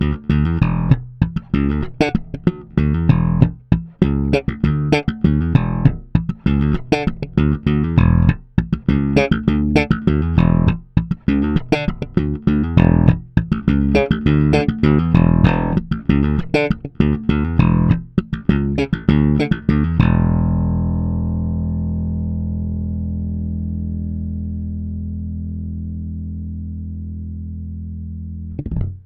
Nahrávky jsou bez kompresoru, linka do zvukovky.
Trochu je slyšet brum, ale to mi dělá dnes elektrika doma.
Slap - oba